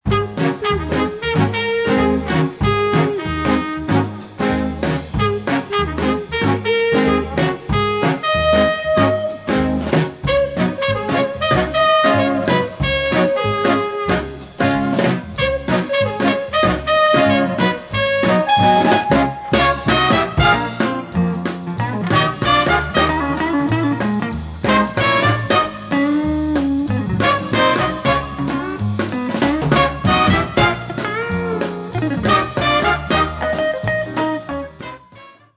Subject: Identify instrumental 'theme' music from 30-second MP3 excerpt
The MP3 excerpt I have was captured from a TV programme several years
Zealand) when the guitar starts to come in toward the end it seems to